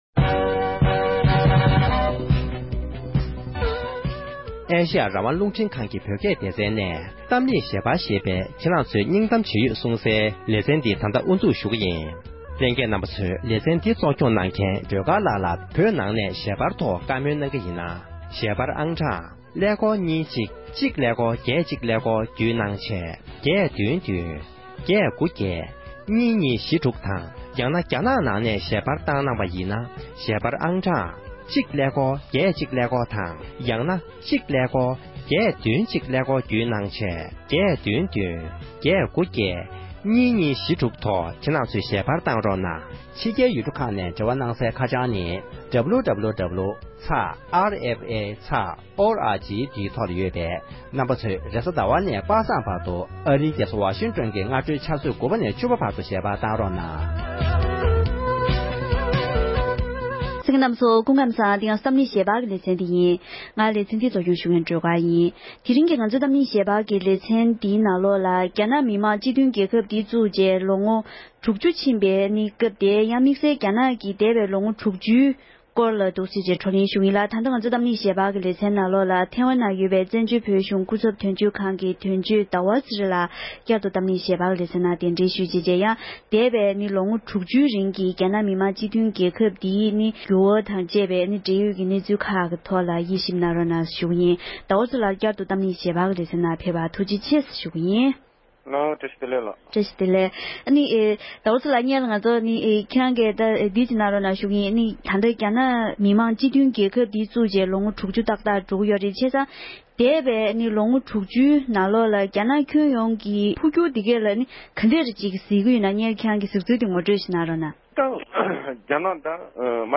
འདས་པའི་ལོ་དྲུག་ཅུའི་རིང་རྒྱ་ནག་ནང་འགྱུར་བ་ཇི་ལྟར་ཕྱིན་ཡོད་མེད་ཐད་བགྲོ་གླེང༌།